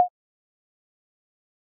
sound effects for the main menu
select.ogg